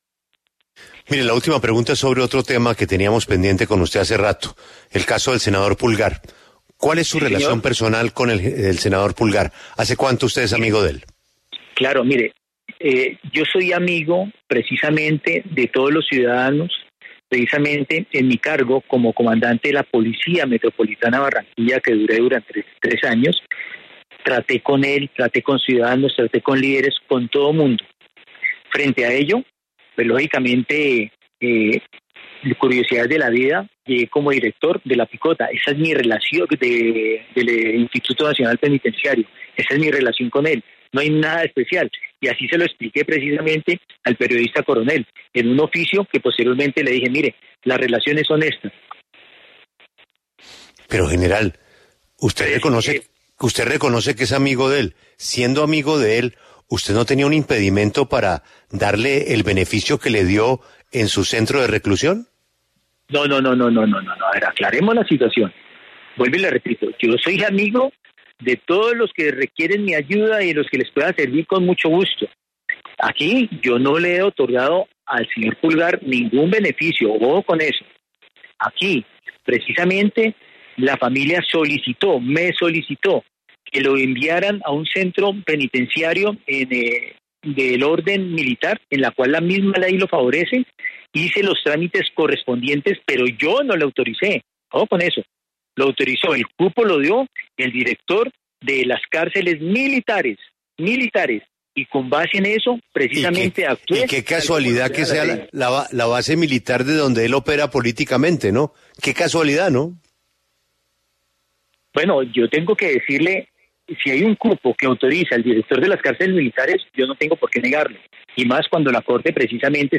Mariano Botero, Mayor General de la Policía que estaba a cargo de la dirección del INPEC hasta hoy, pues fue destituido tras el escándalo en el que están involucrados funcionarios de la institución por las salidas del empresario Carlos Mattos, habló en La W sobre este tema y también acerca de su relación con el senador Eduardo Pulgar y su traslado de prisión.